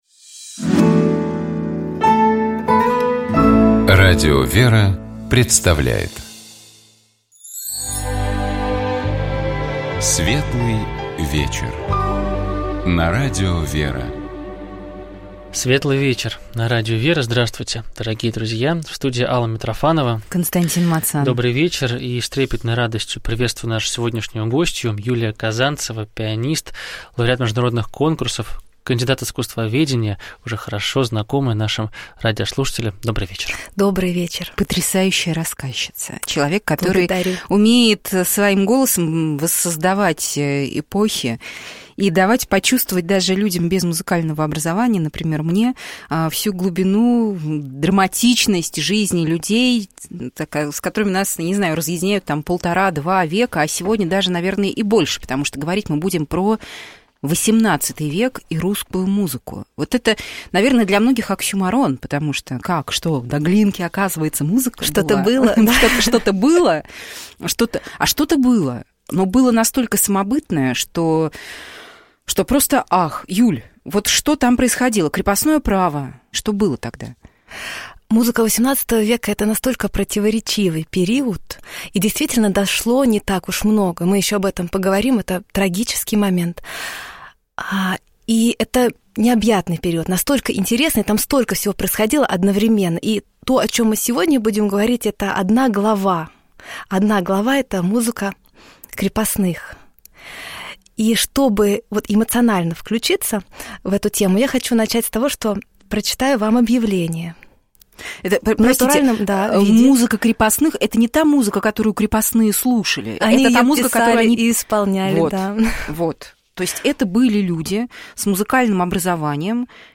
У нас в гостях была кандидат искусствоведения, пианист, лауреат международных конкурсов